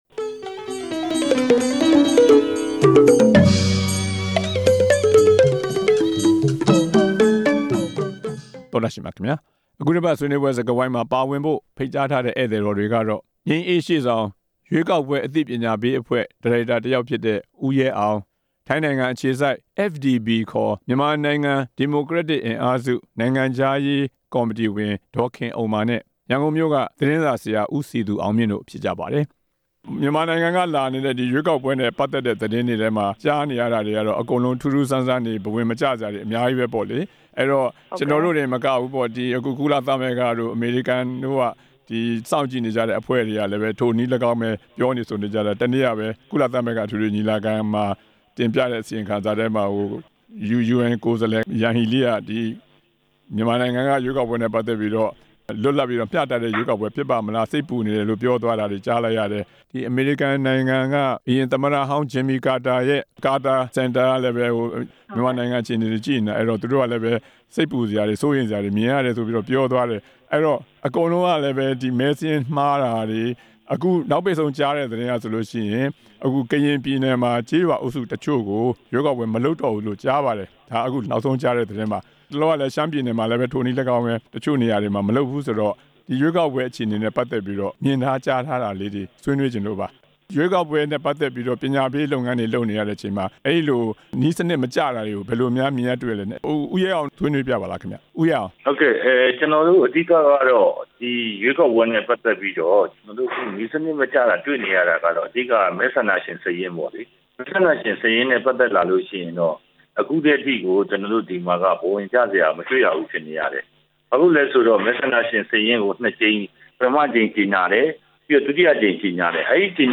ရွေးကောက်ပွဲ ပညာပေးအဖွဲ့ နဲ့ ဆွေးနွေးချက်